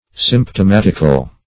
Symptomatic \Symp`tom*at"ic\, Symptomatical \Symp`tom*at"ic*al\,